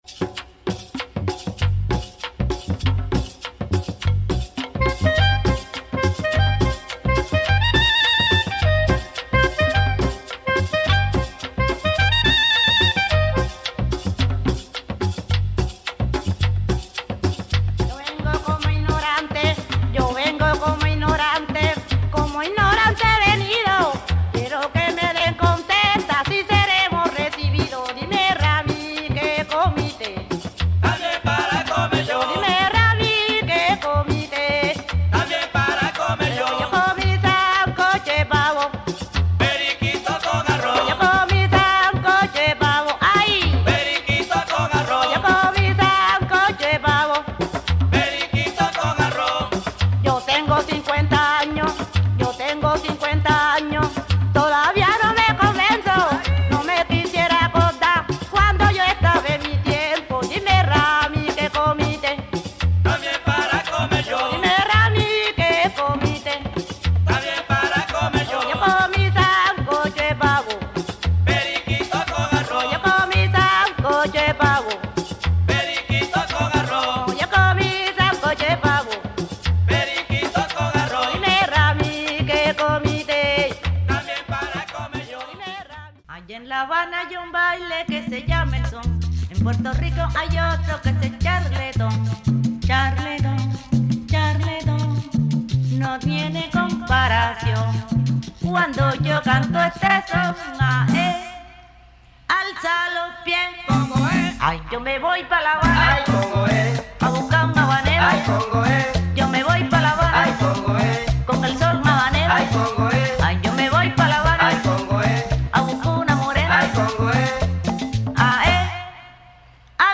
Cumbia
pretty cool tropical tunes